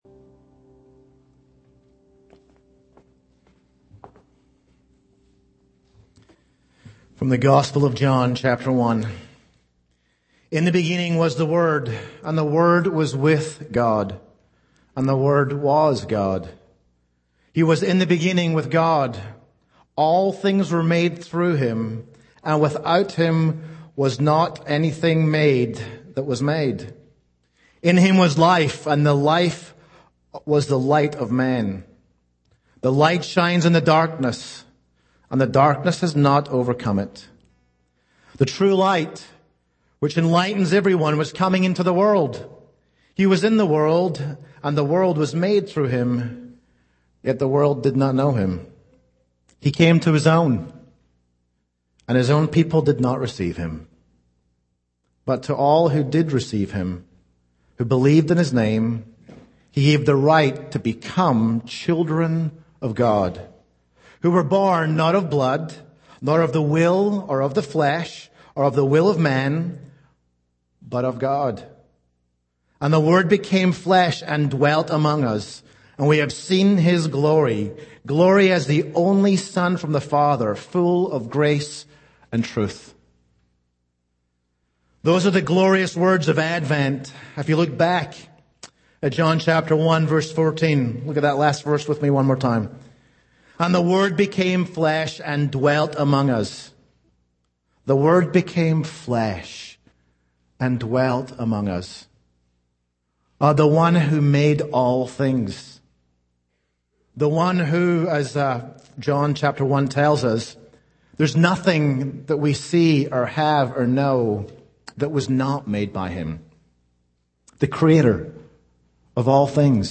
Sermons - Church of the Redeemer